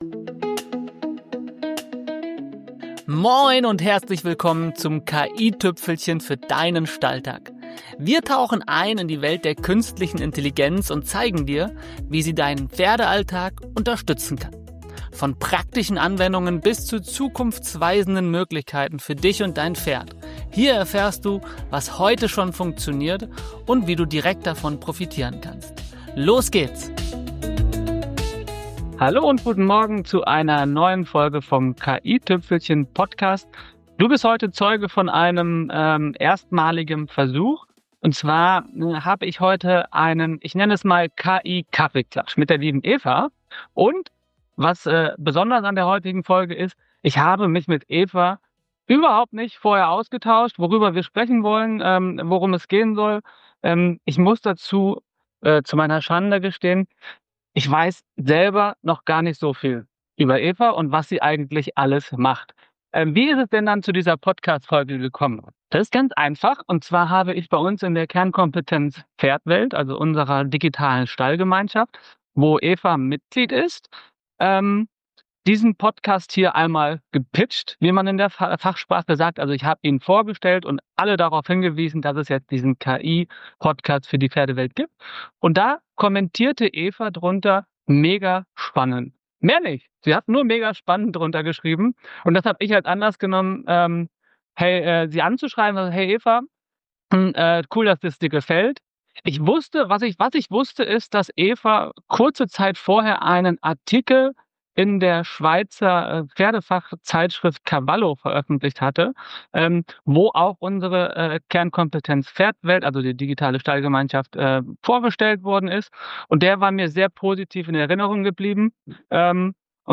Beschreibung vor 3 Monaten In dieser Folge probiere ich etwas völlig Neues aus: den ersten „KI-Kaffeeklatsch“ im KI-Tüpfelchen-Podcast – ohne Vorgespräch, ohne Skript, einfach drauflos quatschen.